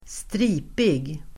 Ladda ner uttalet
Uttal: [²str'i:pig]